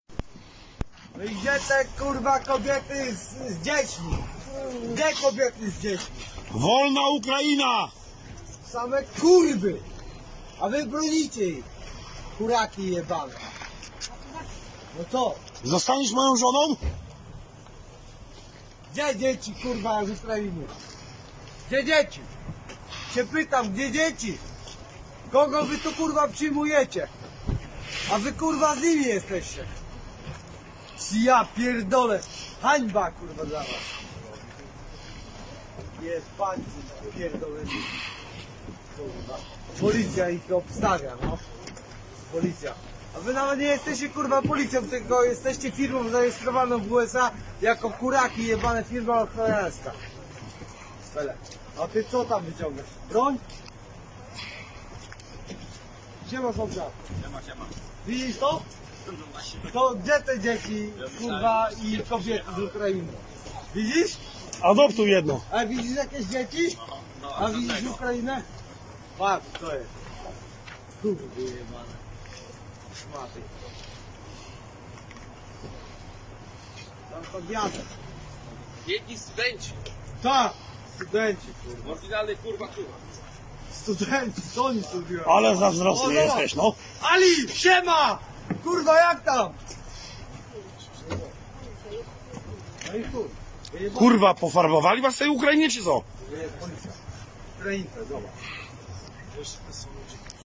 Dies entgeht auch den polnischen Polizisten nicht und sie rufen: "Kurwa gdzie som te dzieci? - Wo zum Teufel sind diese Kinder?"